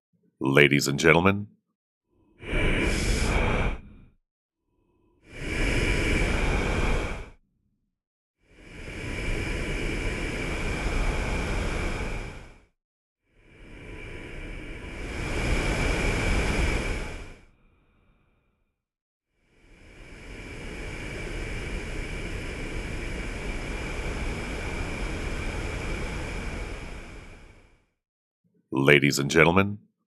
On second thoughts, vocode with Brownian noise rather than white noise.
NB: Any speech will not be intelligible if the Paul Stretch time-resolution is above ~200ms.